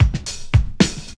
07DR.BREAK.wav